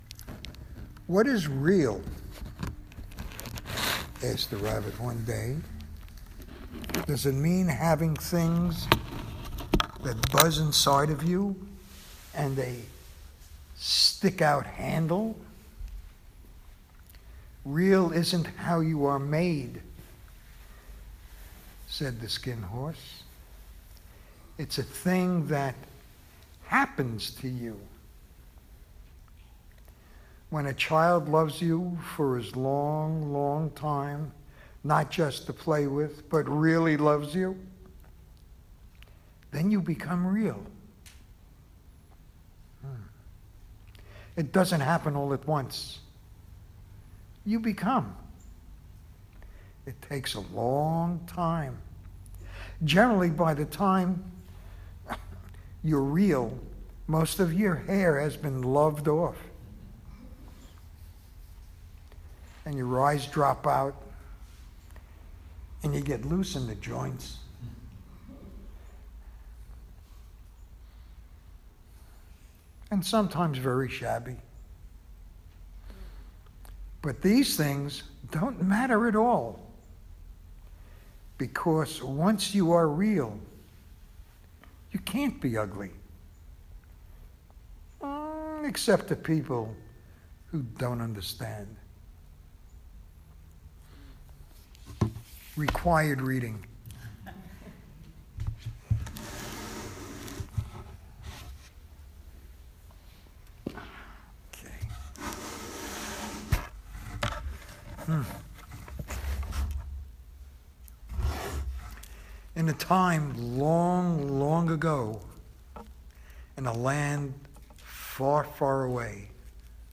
Dharma Talk: Koan Study in Pursuit of Enlightenment